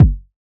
edm-hihat-39.wav